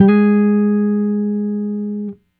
Guitar Slid Octave 09-G#2.wav